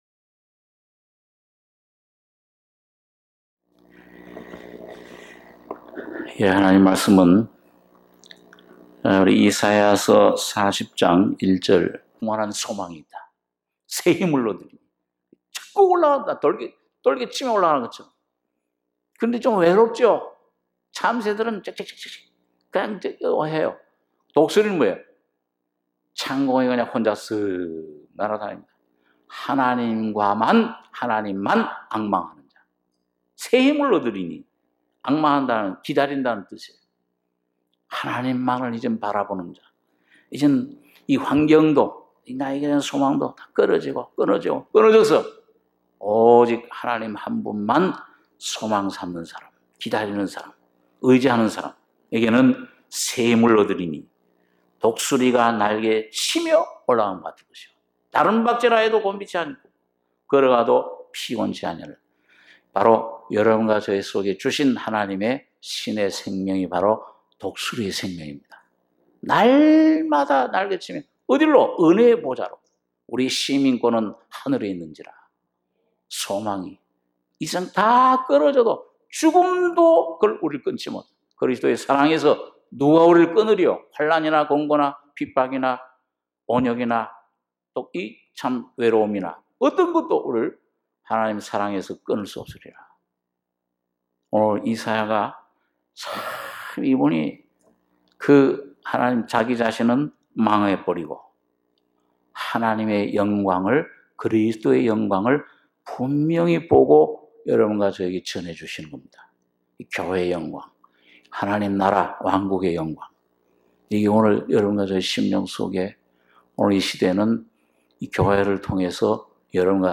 HOME 말씀과찬양 수요예배